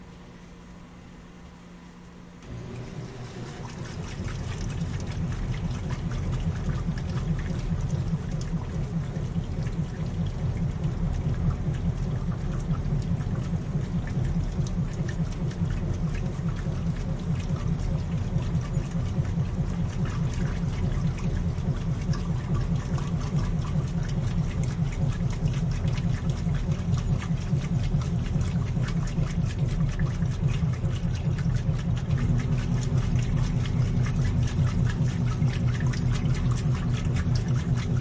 自助洗衣店 " 自助洗衣店 洗衣机 洗涤机 关闭清洗4
描述：洗衣店洗衣机洗衣机关闭wash4.flac
标签： 洗衣店 关闭 洗涤 清洗 垫圈
声道立体声